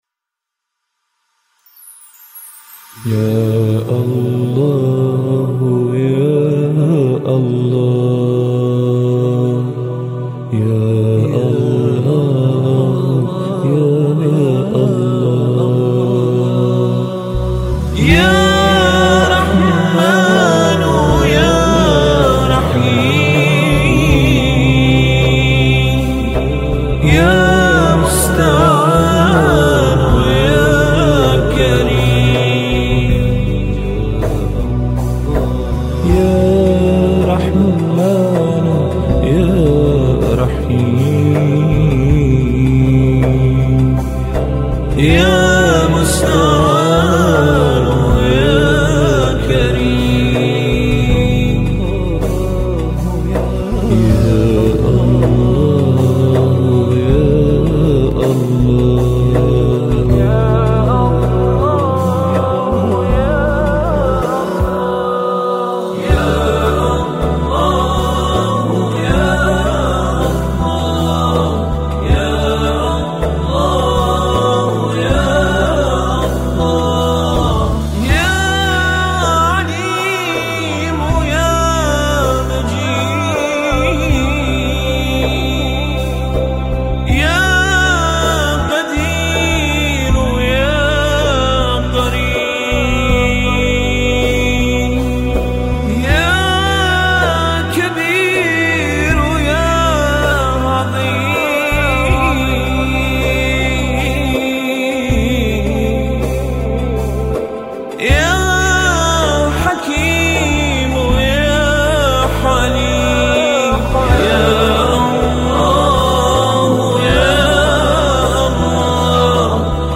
سرودهای ماه رمضان
گروه همخوان